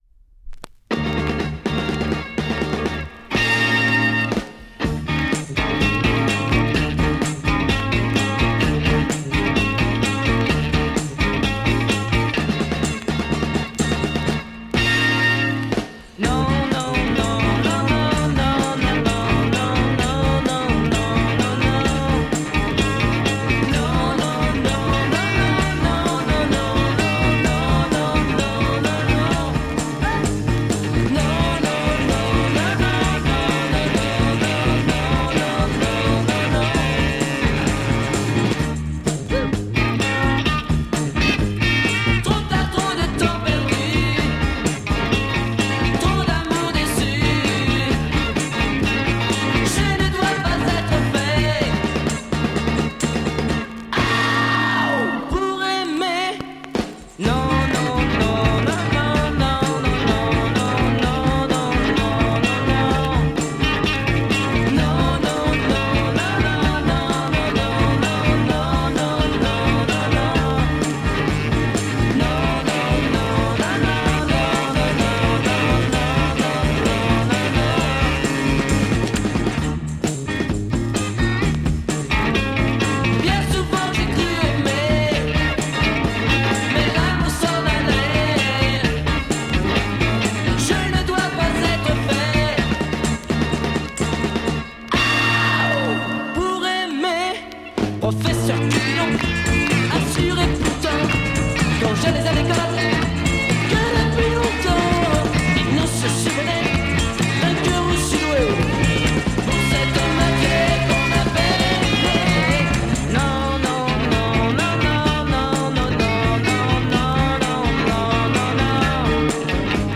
Mega rare French freakbeat Mod promo 7"
Brilliant R&B freakbeat w/guitar break w/Fuzz link.